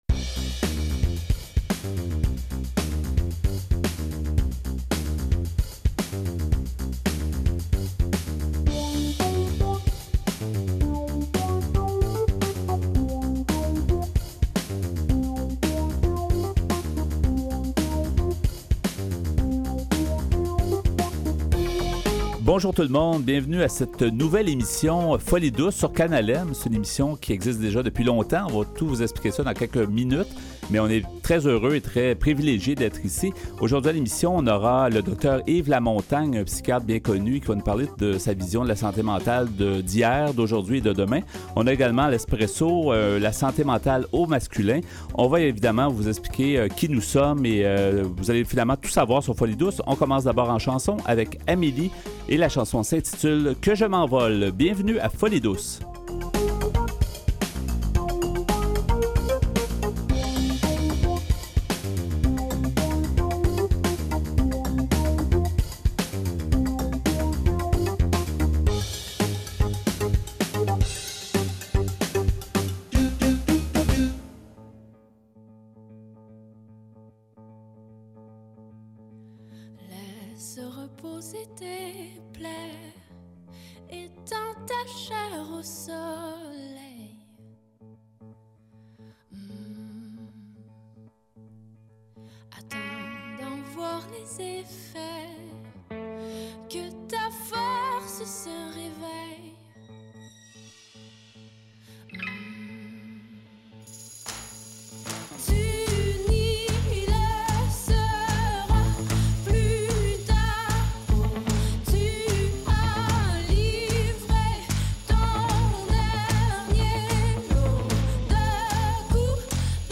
Une entrevue, en deux parties dans notre…